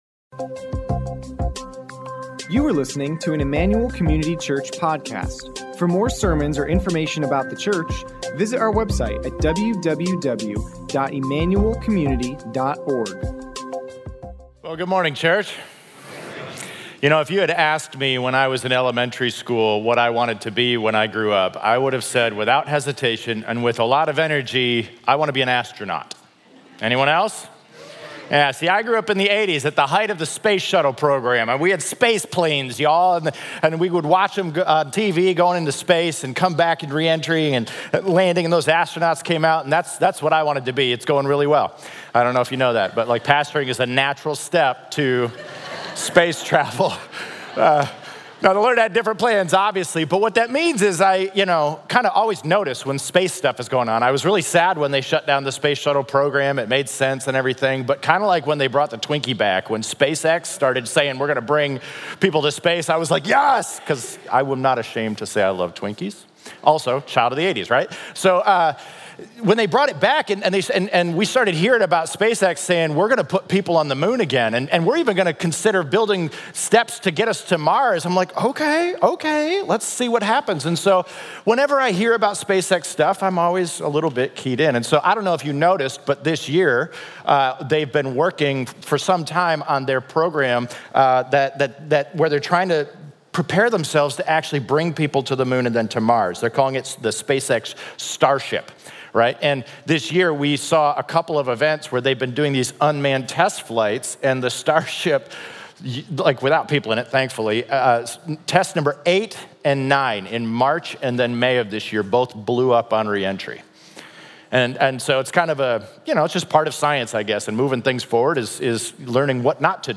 Sermon Notes Completed Notes